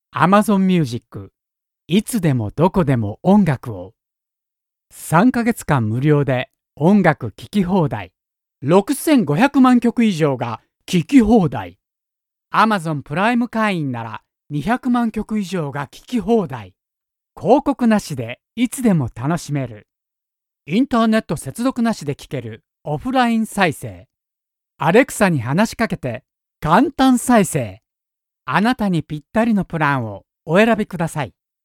Japanese, Male, Home Studio, 30s-50s
Home Studio Read